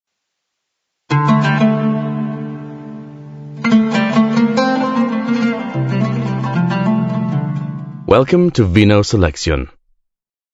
• the power of audio (ASMR + Voice Acting + Soundscapes)